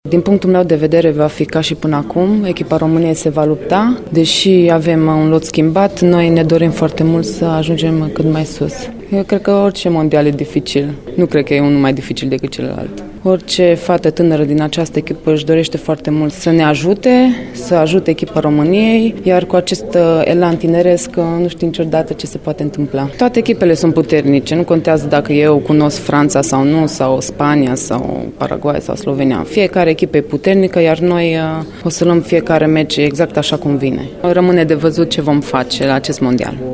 Dintre jucătoarele cu experiență au mai vorbit, înainte de plecarea spre Germania, apărătoarea Gabriella Szucs și interul dreapta Melinda Geiger:
Geiger-despre-nationala-si-MOndial.mp3